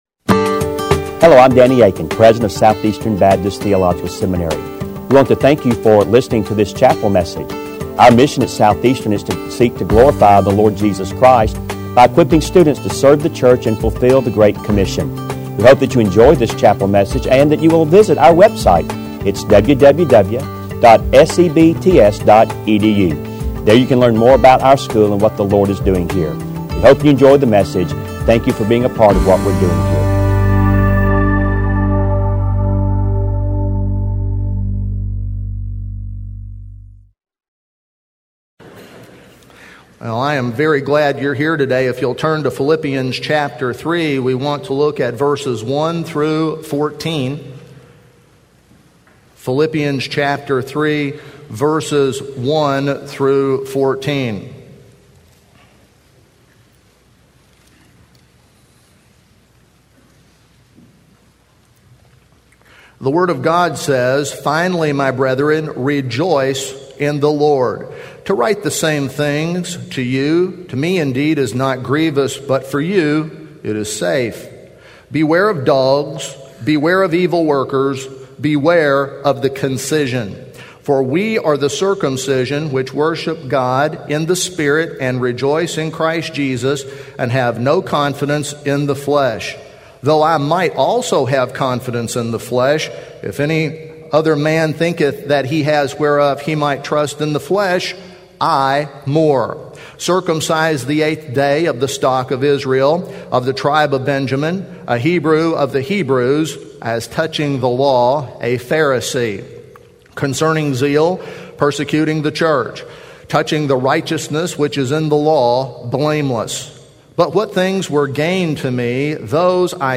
In Collection: SEBTS Chapel and Special Event Recordings - 2000s